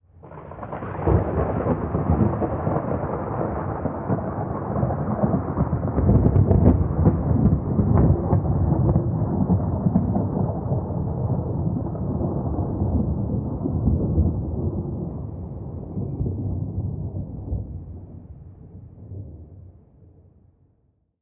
thunder-48k.wav